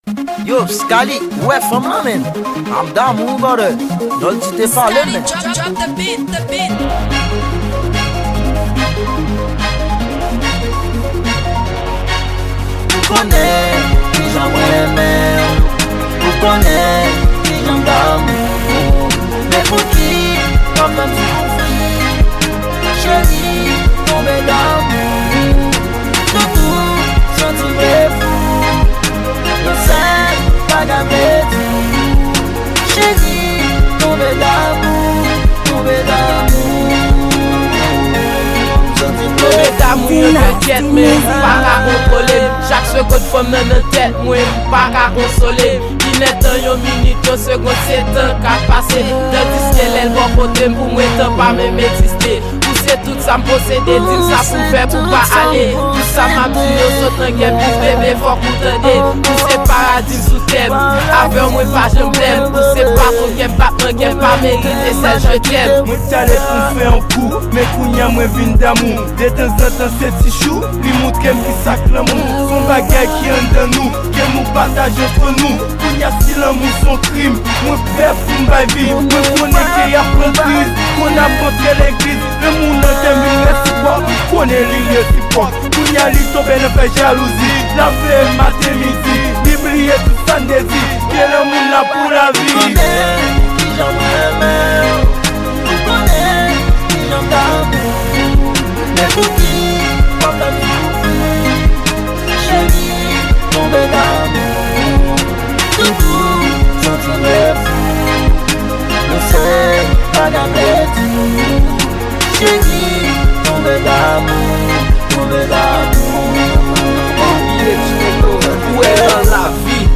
Genre: Rap-Rnb.